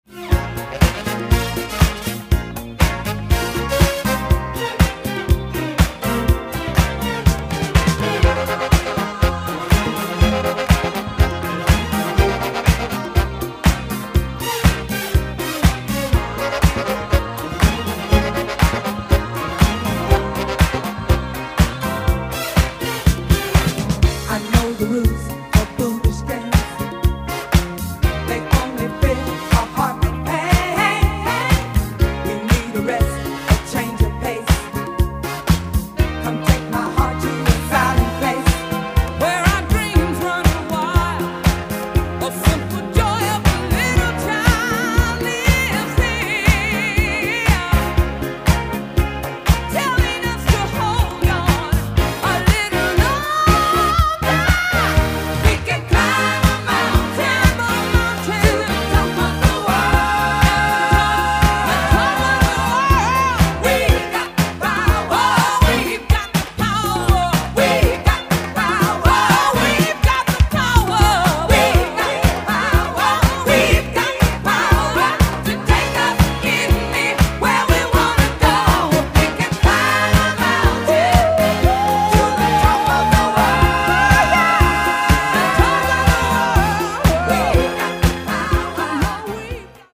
ダンサブルでメロディアスな展開も最高な一発！